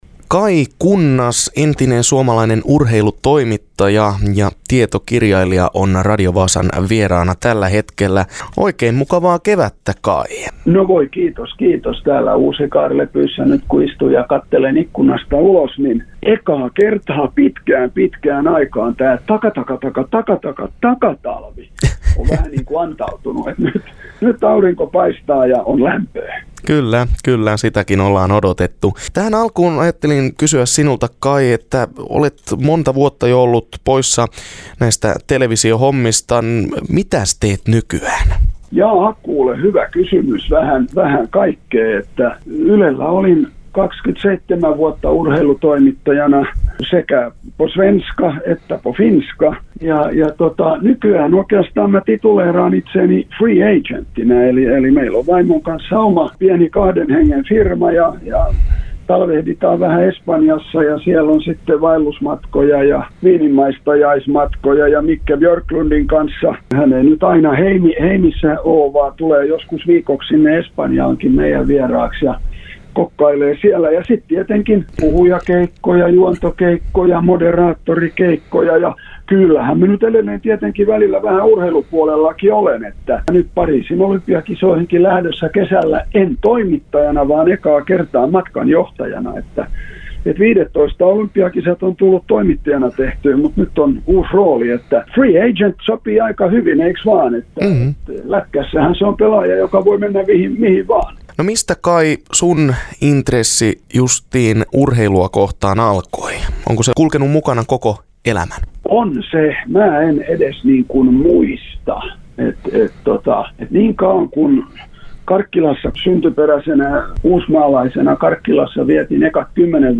Kaj Kunnas intervjuas